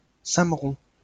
Sammeron (French pronunciation: [samʁɔ̃]